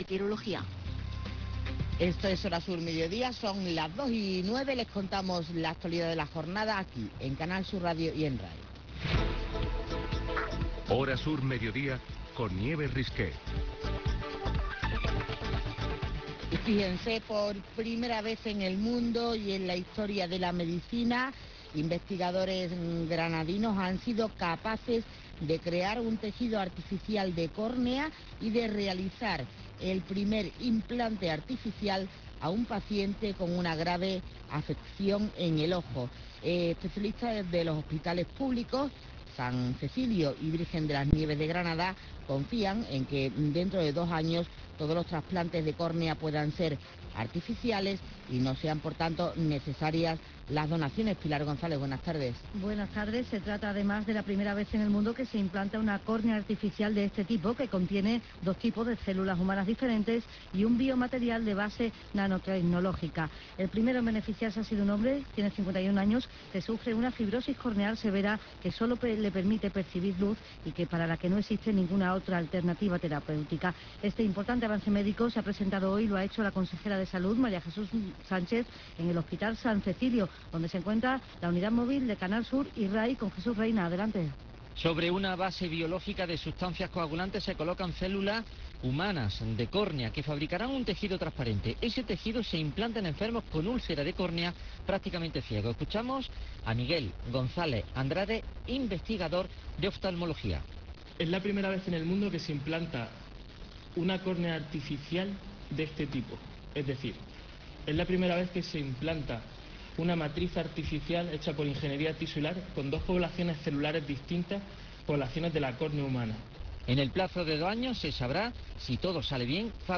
DECL. MARIA JOSE SANCHEZ, CONSEJERA SALUD